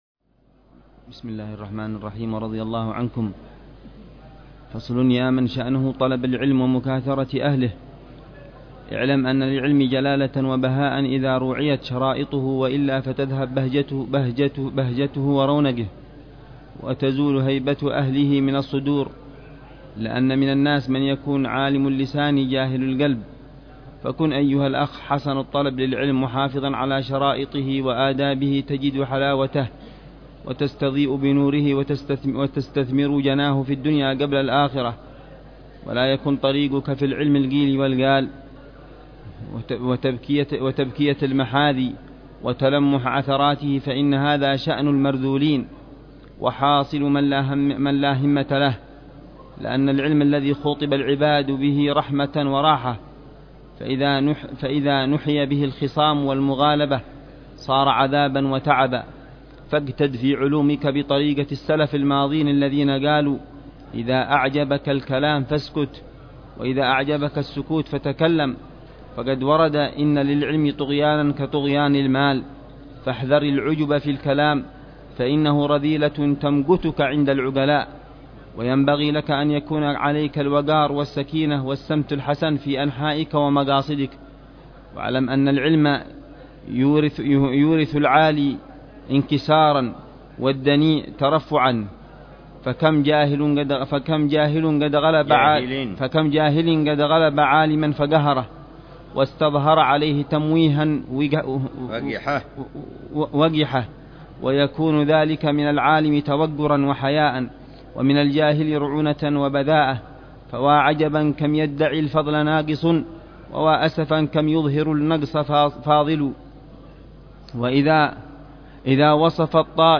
شرح للحبيب عمر بن حفيظ على كتاب إيضاح أسرار علوم المقربين للإمام محمد بن عبد الله بن شيخ العيدروس، الذي اهتم بتوضيح معالم طريق السالكين والعبا